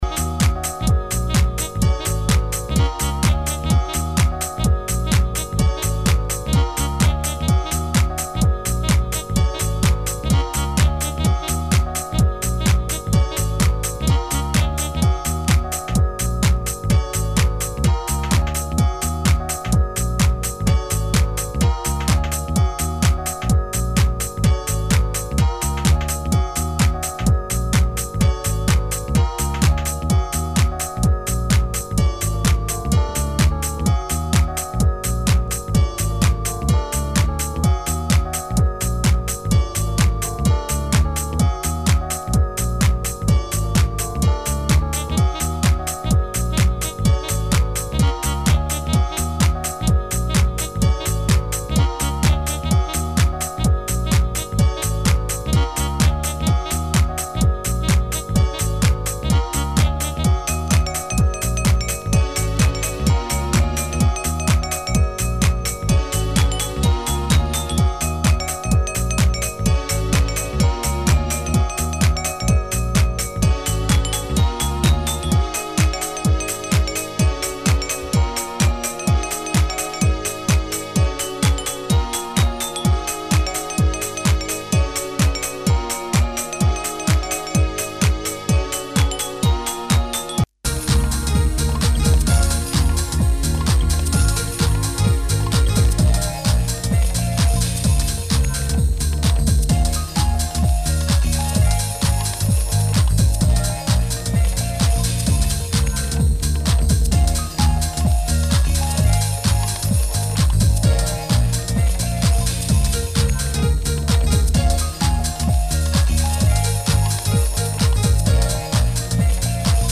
House-Trance